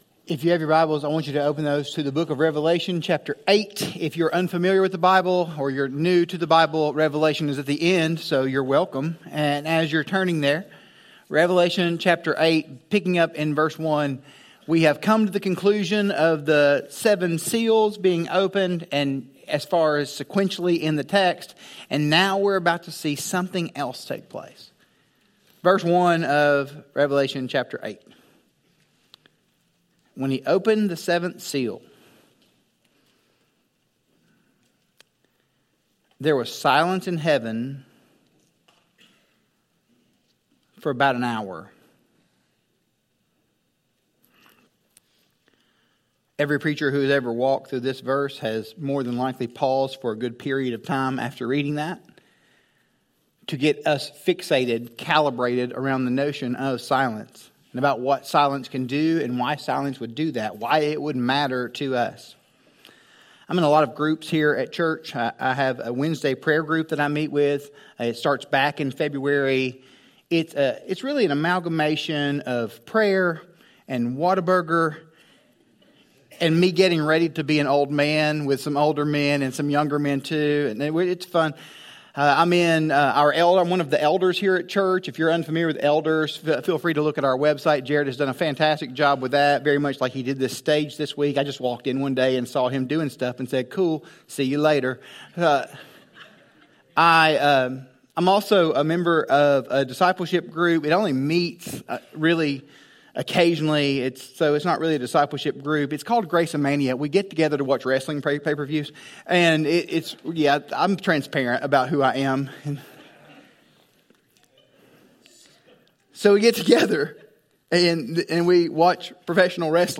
Grace Bible Church Sermon Audio
Sermon audio from Grace Bible Church in Clute, TX … continue reading 99 епізодів # Religion # Bible # Audio # Teaching # Baptist # Preaching # GBC # Christianity # Sermons